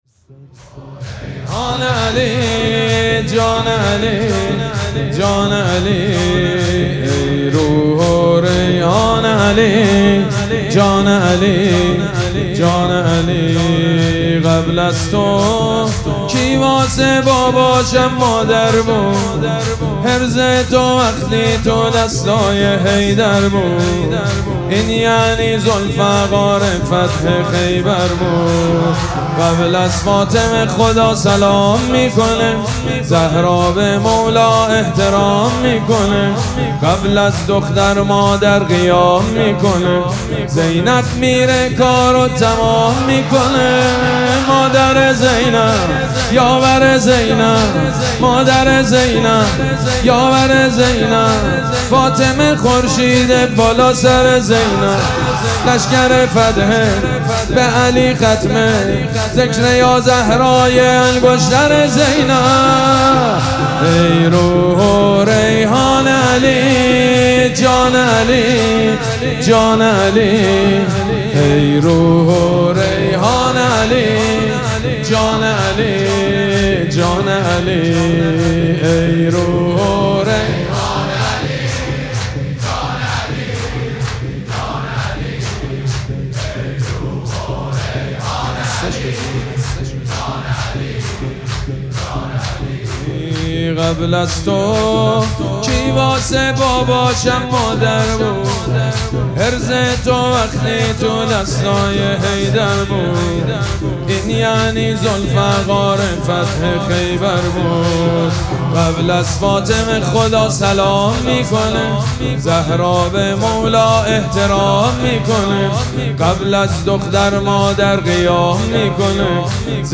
حاج سيد مجید بنی فاطمه
ای روح و ریحان علی جان علی - مداحی شور حضرت زهرا (س) شب پنجم مراسم فاطمیه 1403 سید مجید بنی فاطمه
دانلود فایل صوتی مداحی جدید نوحه شور شهادت حضرت زهرا شب پنجم مراسم فاطمیه 1403 حاج سید مجید بنی فاطمه ای روح و ریحان علی جان علی
شب پنجم فاطمیه 1403